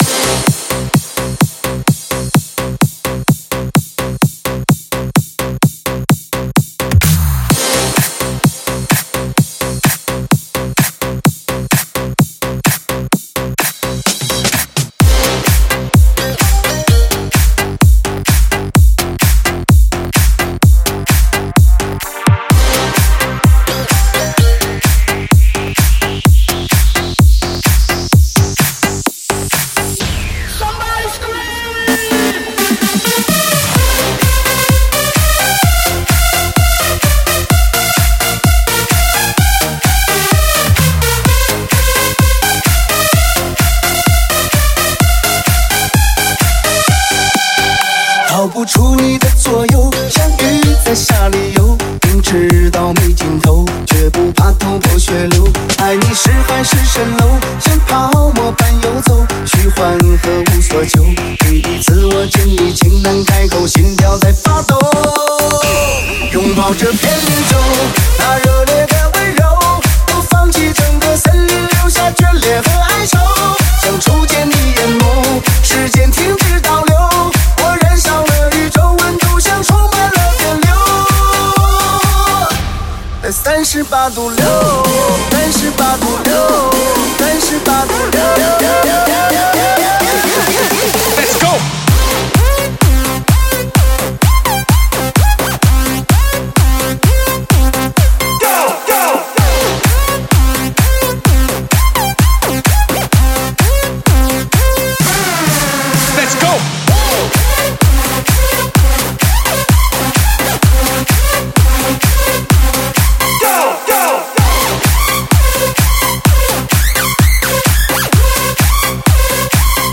确实劲爆
非常劲爆的节奏感